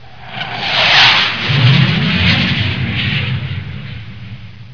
دانلود آهنگ طیاره 44 از افکت صوتی حمل و نقل
جلوه های صوتی
دانلود صدای طیاره 44 از ساعد نیوز با لینک مستقیم و کیفیت بالا